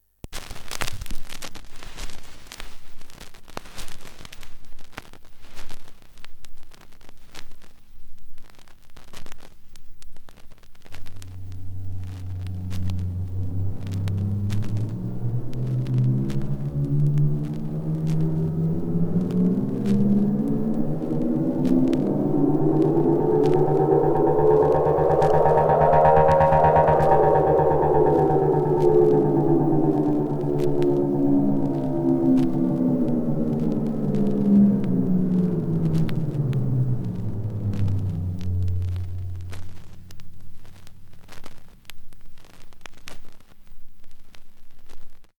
A recording from an LP :